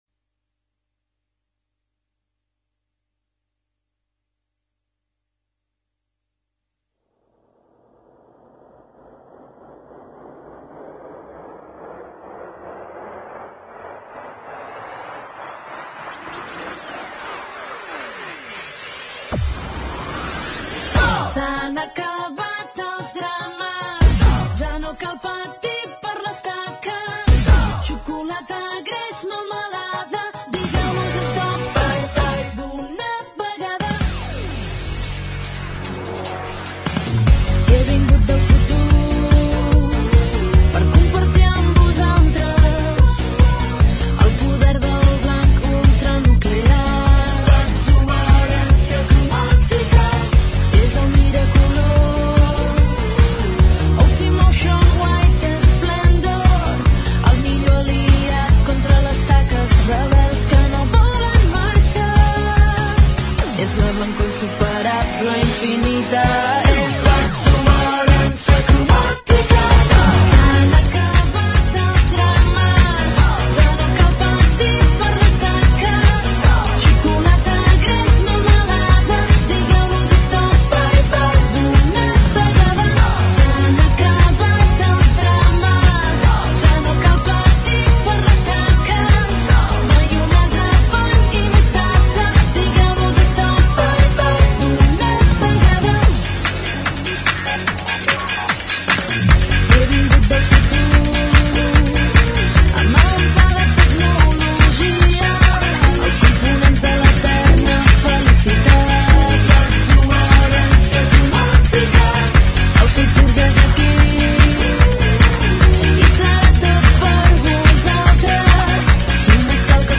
hardtek
hardtecno , hardcore